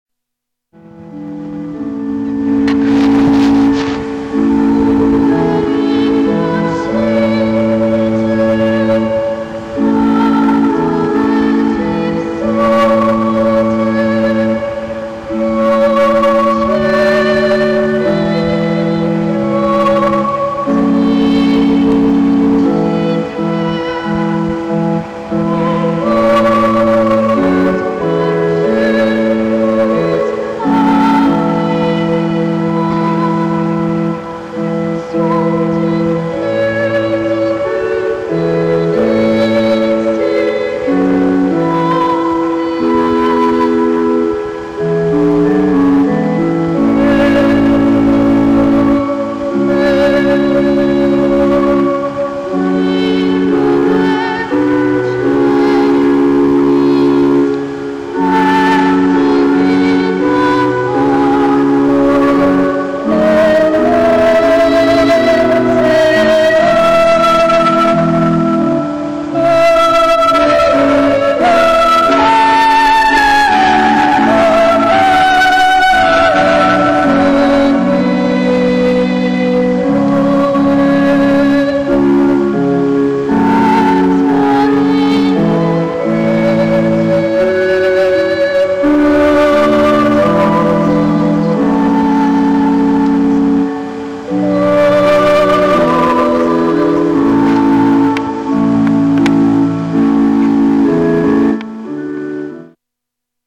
French Boy Chorister: Musical Excerpts
He tell us, "Here are some extracts I sang in solo.